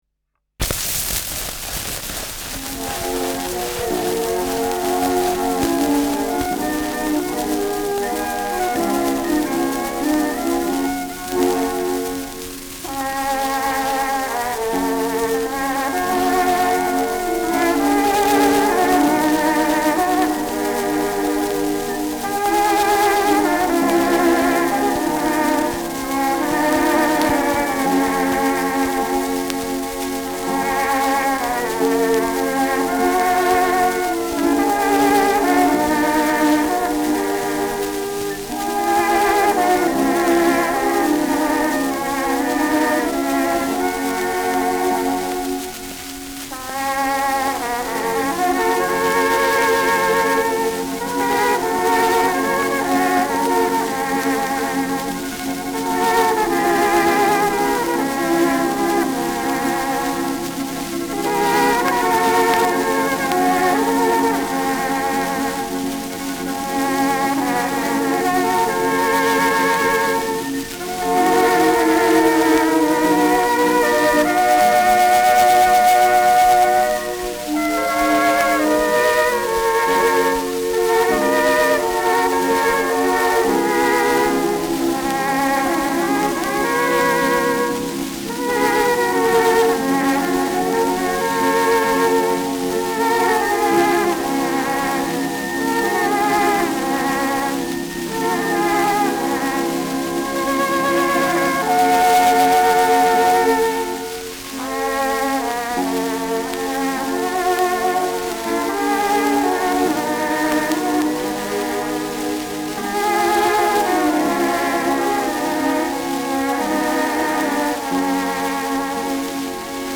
Schellackplatte
präsentes Rauschen : präsentes Knistern : abgespielt : leiert
Original Wiener Schrammel-Quartett (Interpretation)
[Wien] (Aufnahmeort)